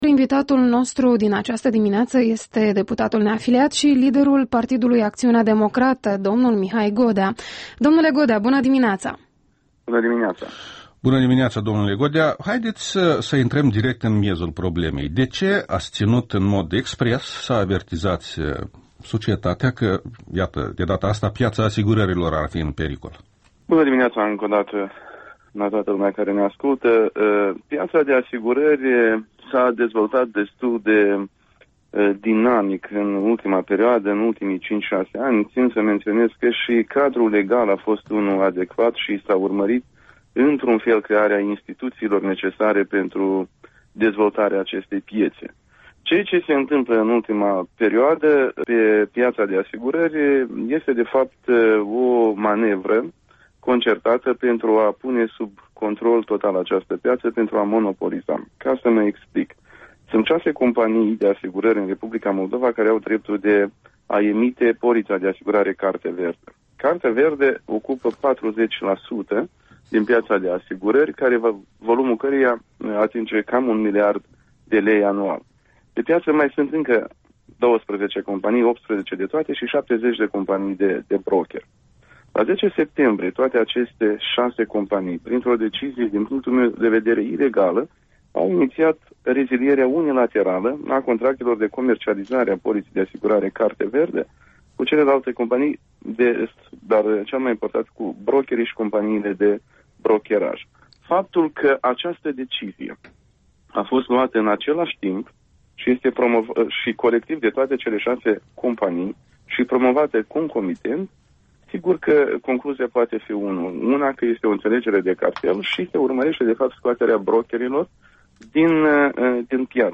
Interviul dimineții la REL: cu Mihai Godea, președintele Partidului Acțiunea Democratică